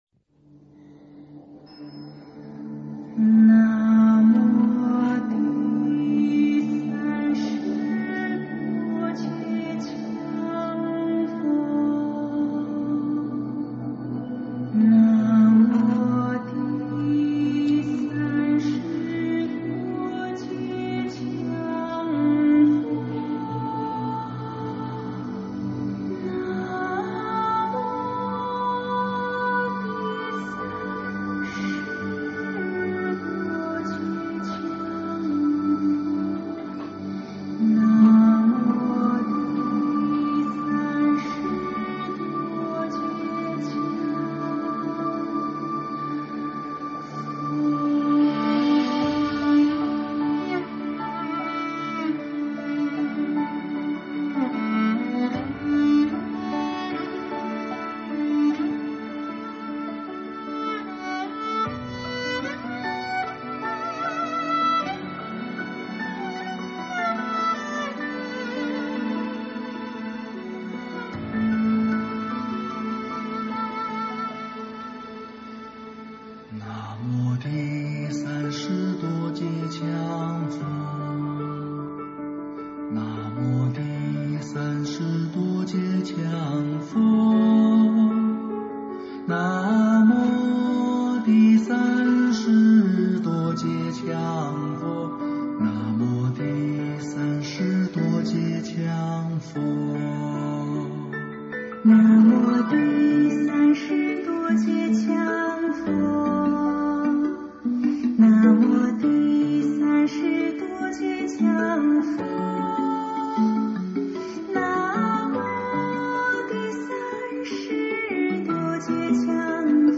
【佛号唱诵】南无羌佛